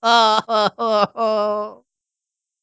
03_luigi_dying.aiff